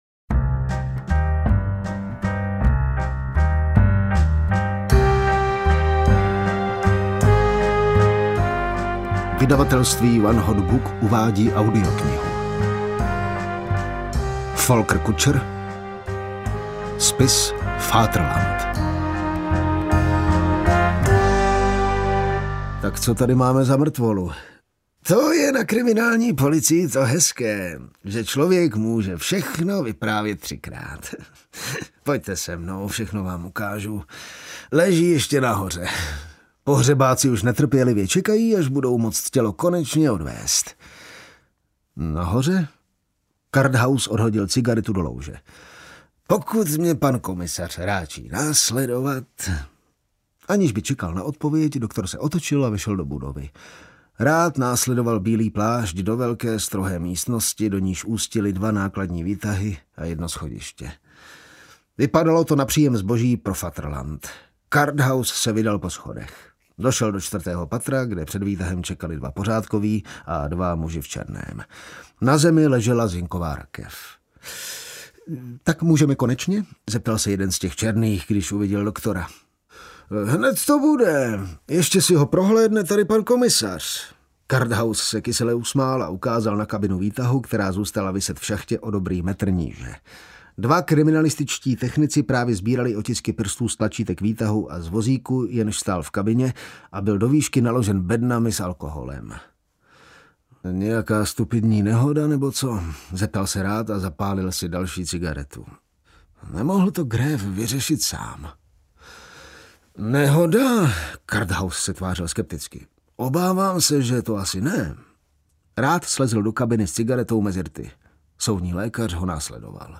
Spis Vaterland audiokniha
Ukázka z knihy
• InterpretJan Teplý ml.